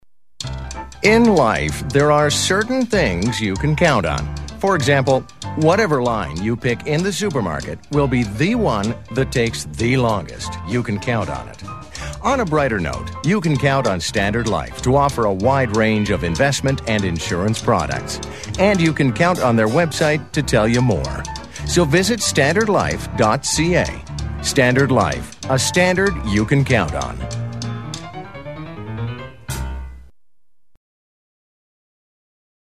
Commercial - EN